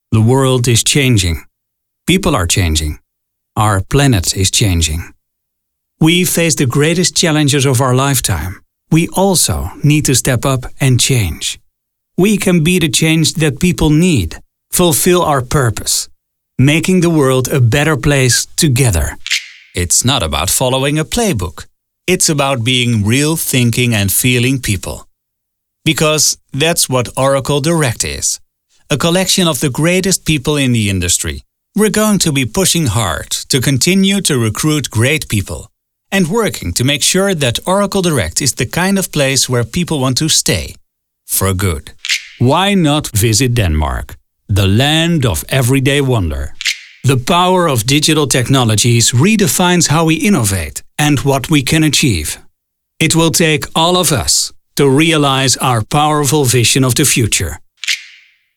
[European English] Corporate narration in international English with a European twist
Middle Aged